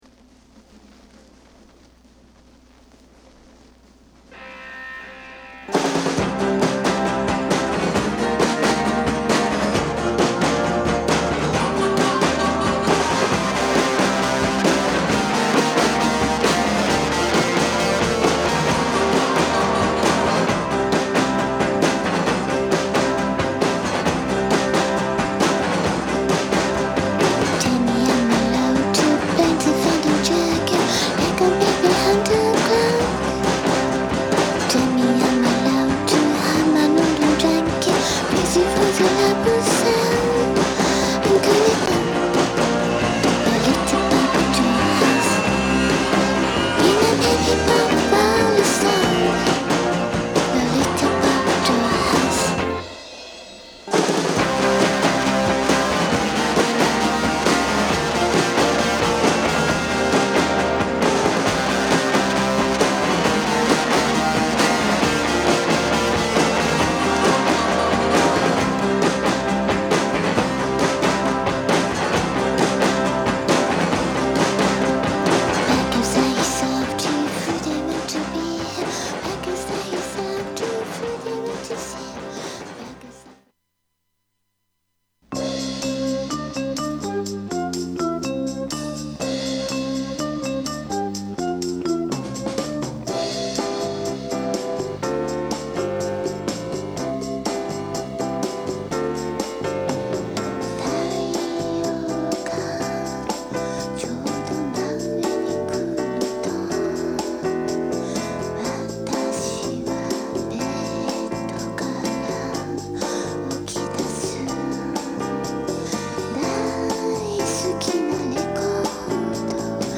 Lounge, Acoustic, Synth-pop